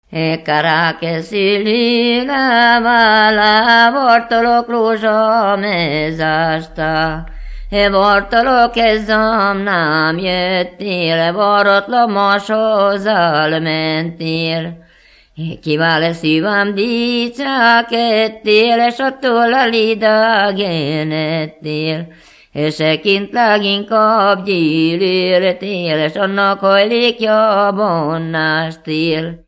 Moldva és Bukovina - Moldva - Klézse
ének
Stílus: 6. Duda-kanász mulattató stílus
Szótagszám: 8.8.8.8
Kadencia: 4 (1) 2 1